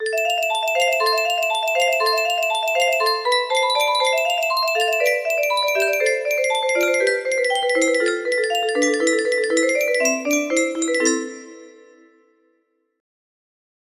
Le coucou（カッコウ） music box melody
Le coucou [Rondeau] L.C.Daquin